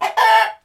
loudcluck5.wav